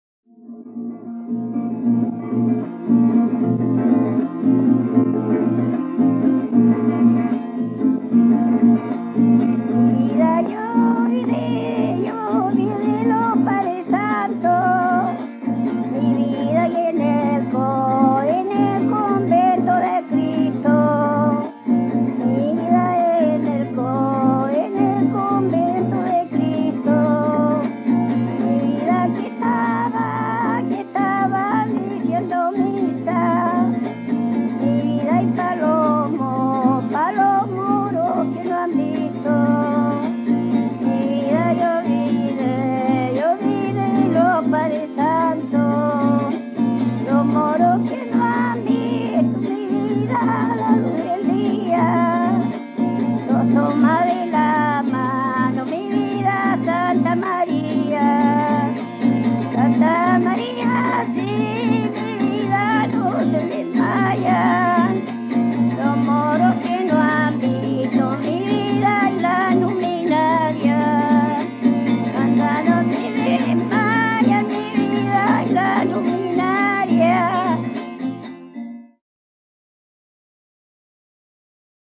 quien se acompaña con una guitarra afinada por la orilla.
Música tradicional
Cueca
Música folclórica